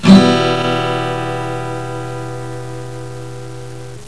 virtual guitar
Em